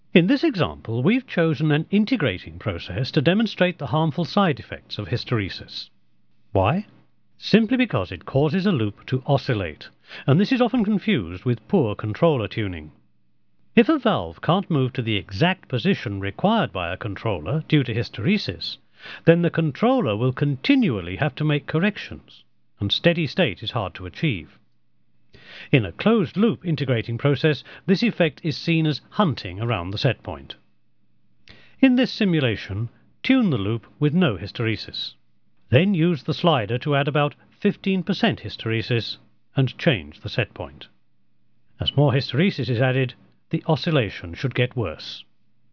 Teeafit Sound & Vision has a sophisicated audio facility, idea for the recording of spoken-word material.
Technical voiceovers are a speciality. This is from a training CD-Rom on Chemical Process Control Systems for an international client.